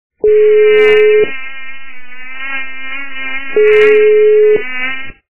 » Звуки » Природа животные » Муха - Жужжание мухи
При прослушивании Муха - Жужжание мухи качество понижено и присутствуют гудки.
Звук Муха - Жужжание мухи